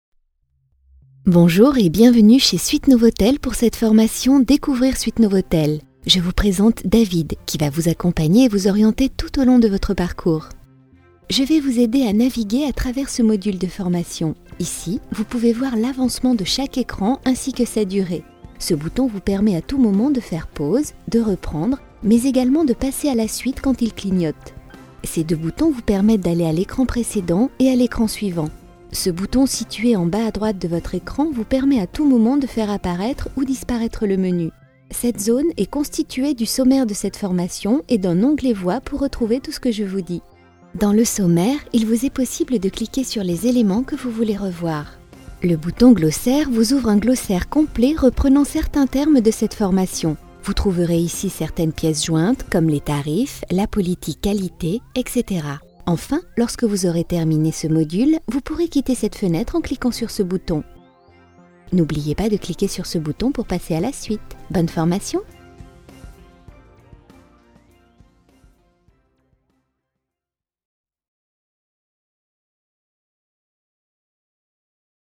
Comédienne voix-off professionnelle tout type de voix et de projet.
Sprechprobe: eLearning (Muttersprache):
Professional voiceover actress all types of voice and project.